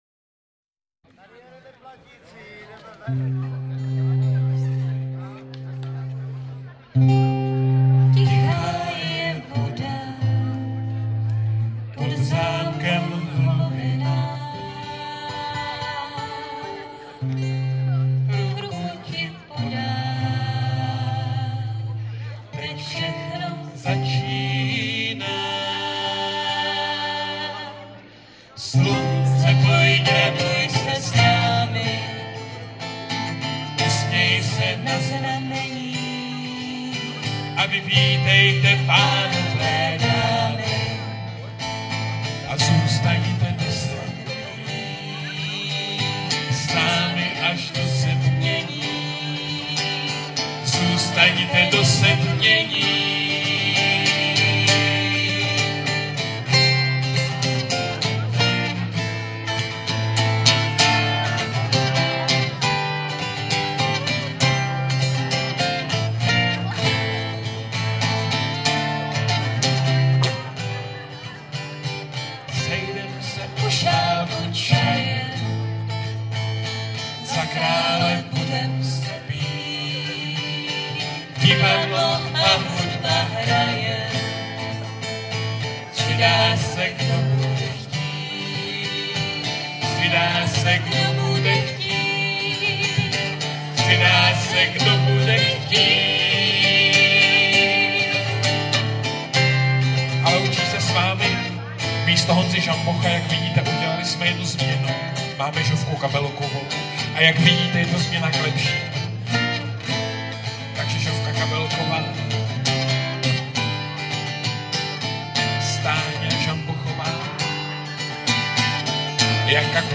Hymna z 1.ročníku festivalu (1.4 MB, formát mp3)